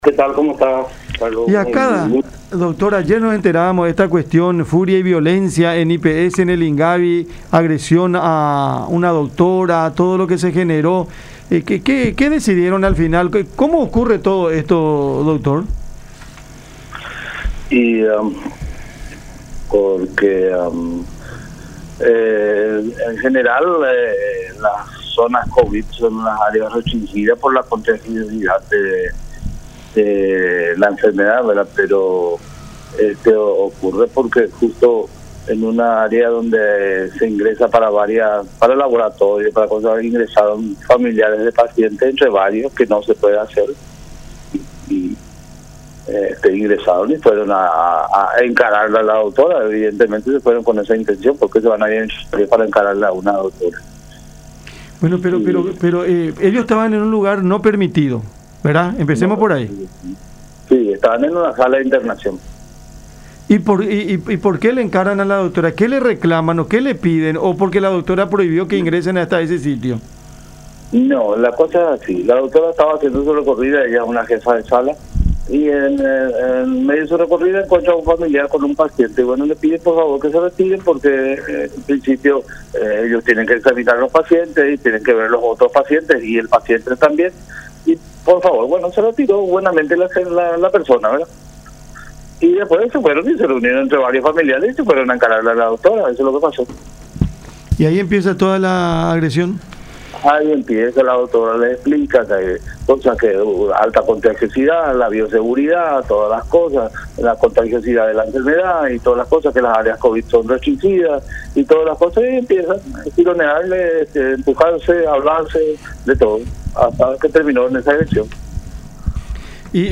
en diálogo con Cada Mañana por La Unión.